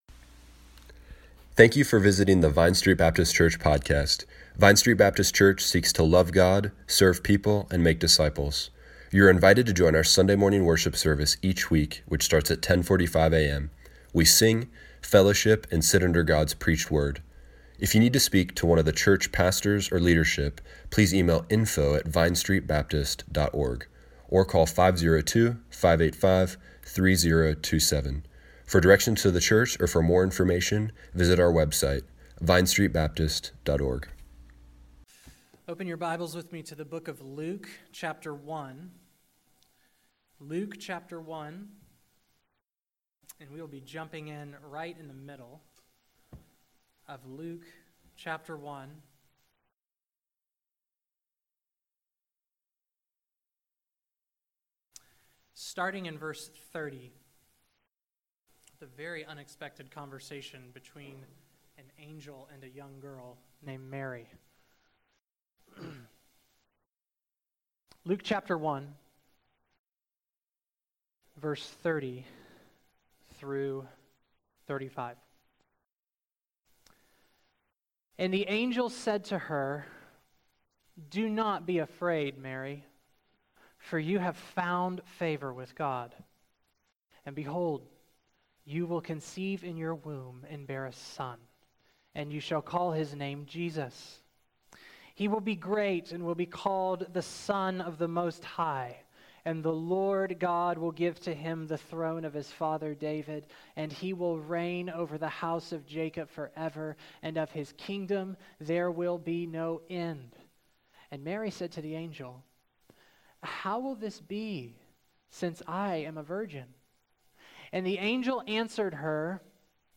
Service Morning Worship